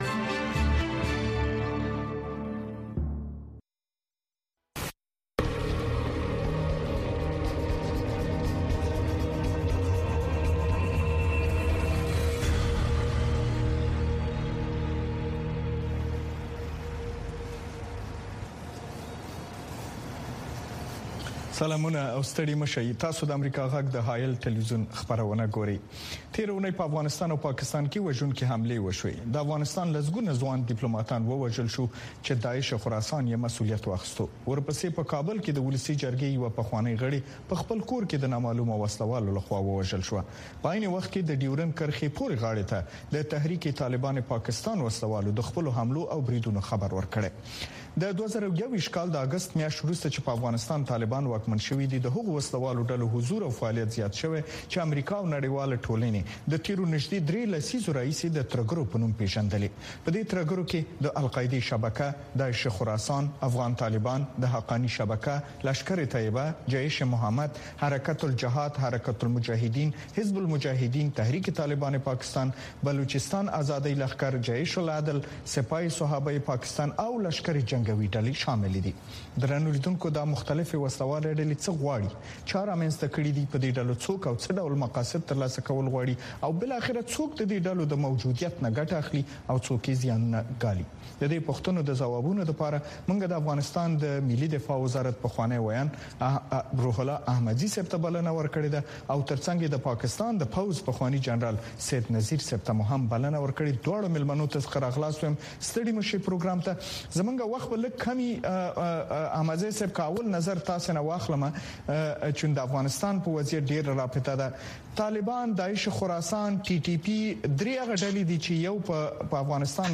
په دغه خپرونه کې د بېلابېلو اړخونو سره په مخامخ، ژورو او تودو بحثونو کې د افغانستان، سیمې، او نړۍ مهم سیاسي، امنیتي، اقتصادي، او ټولنیز موضوعات څېړل کېږي.